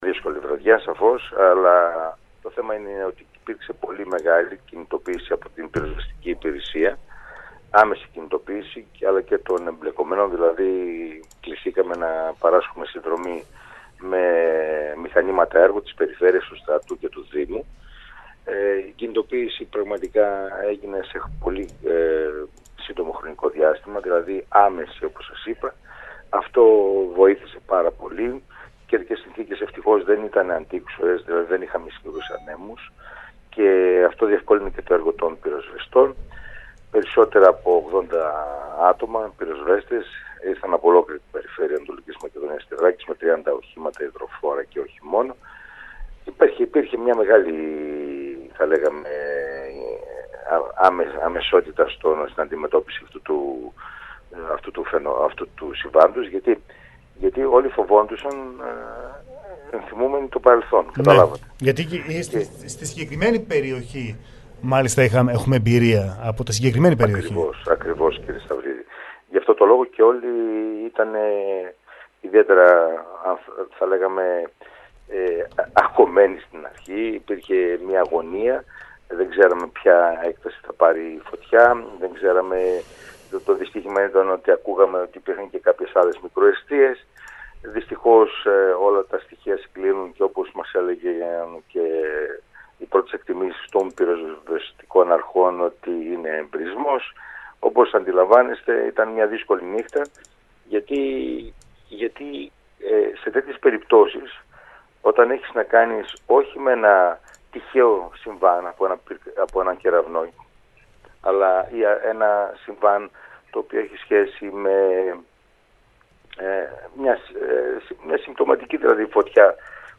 Ακούστε τι είπε ο Δημήτρης Πέτροβιτς: